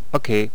archer_ack1.wav